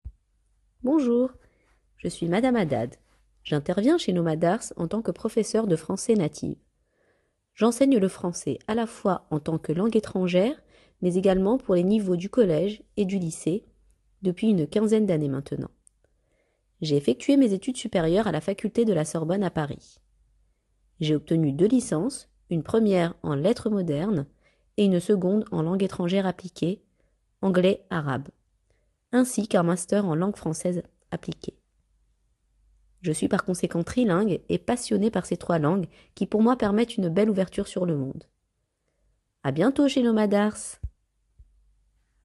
Les professeurs de français vous parlent!